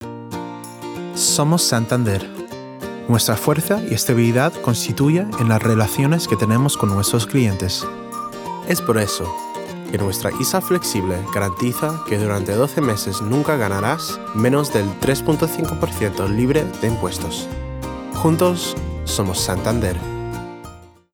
Warm, Trusting, Friendly